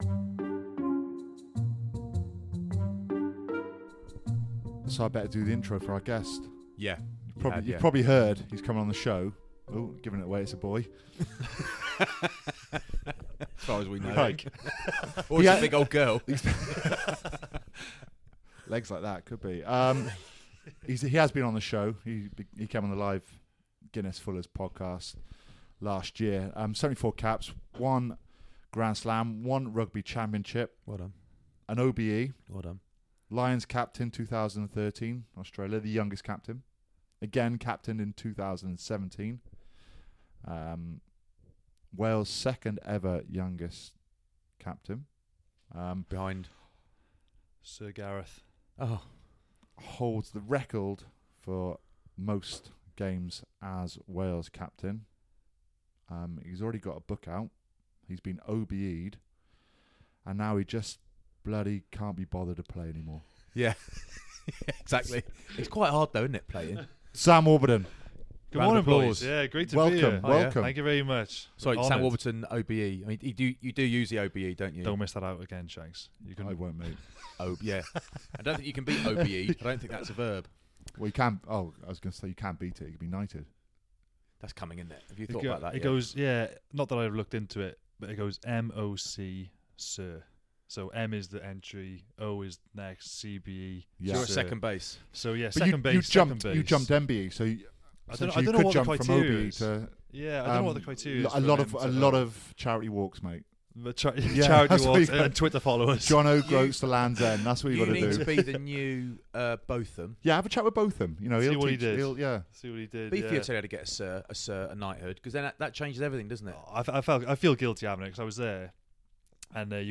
Sam Warburton OBE interview